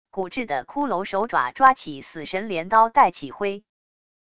骨质的骷髅手爪抓起死神镰刀带起灰wav音频